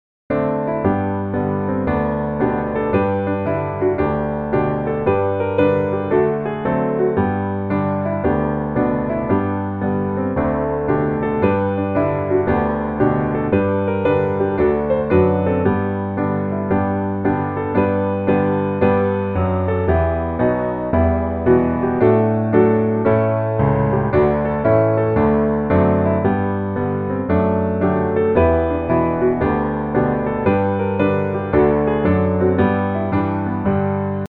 Gospel
G Major